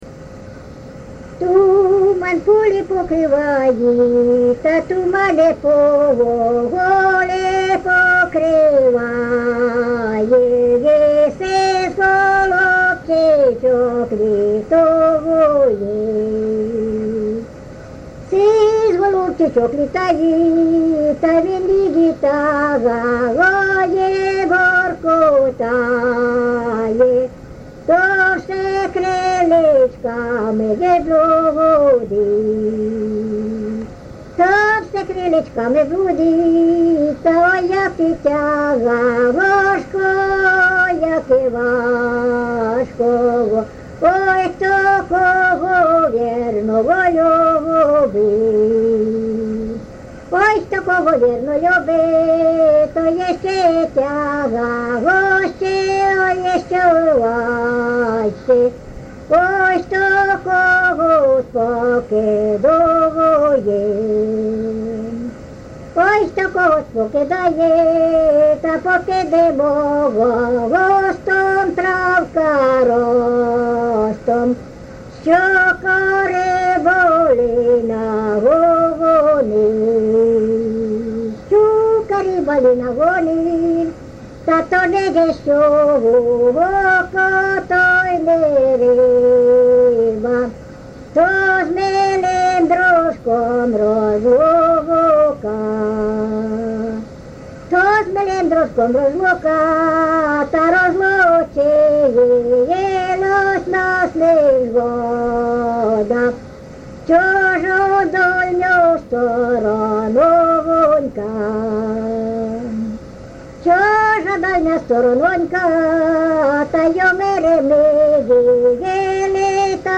ЖанрПісні з особистого та родинного життя
Місце записус. Ярмолинці, Роменський район, Сумська обл., Україна, Слобожанщина